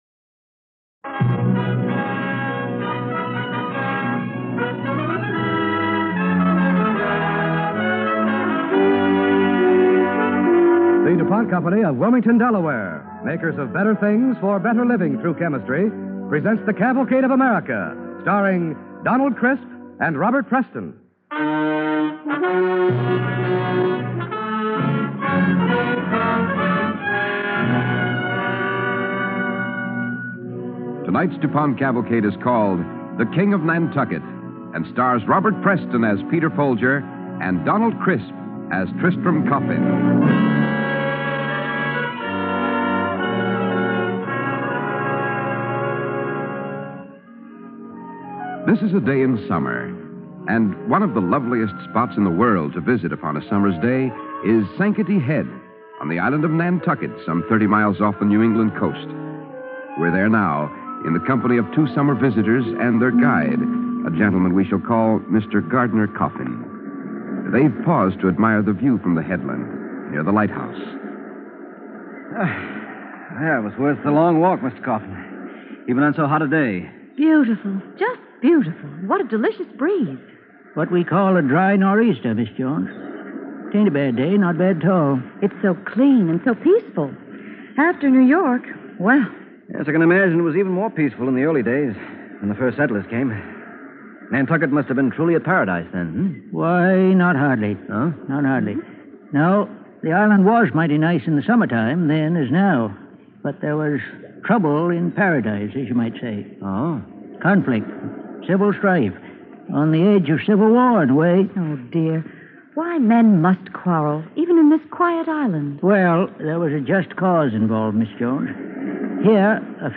starring Robert Preston and Donald Crisp
Cavalcade of America Radio Program